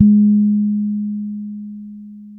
-MM DUB  G#4.wav